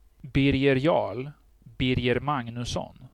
kuuntele ääntämys (ohje)
Sv-Birger_Jarl.ogg.mp3